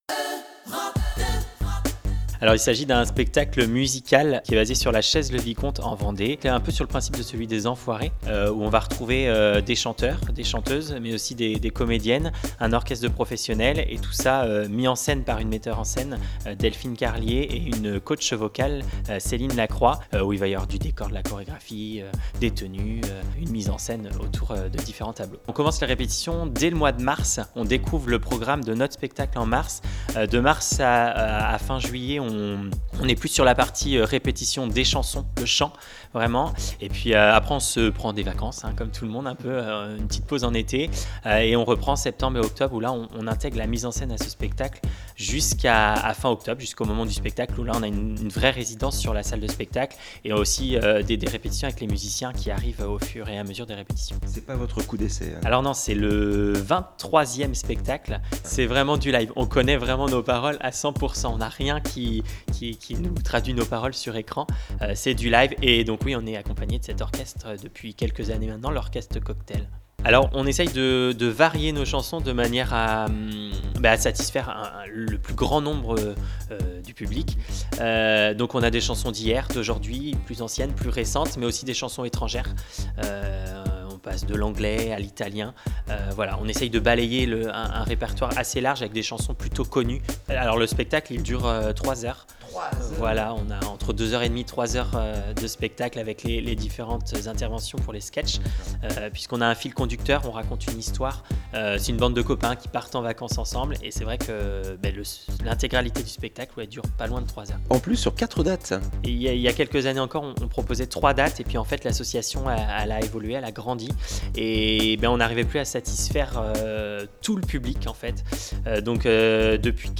au micro EUROPE 2